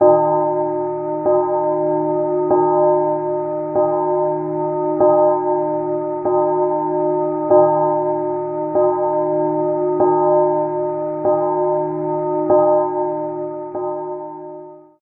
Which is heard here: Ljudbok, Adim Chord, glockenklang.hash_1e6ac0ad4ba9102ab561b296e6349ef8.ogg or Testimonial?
glockenklang.hash_1e6ac0ad4ba9102ab561b296e6349ef8.ogg